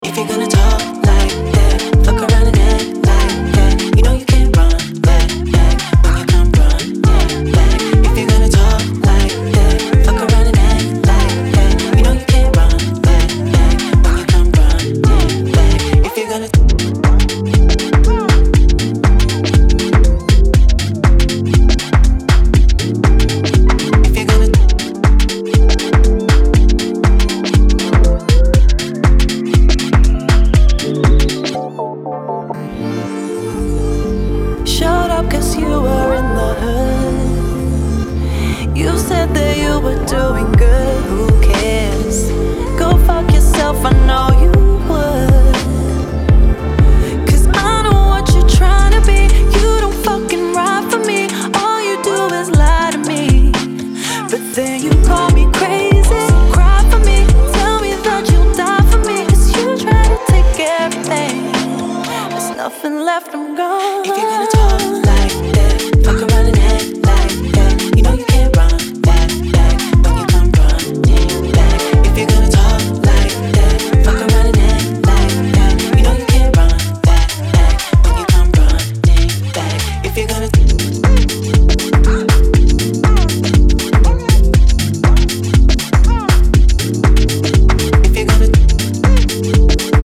ハーフテンポの導入で魅せる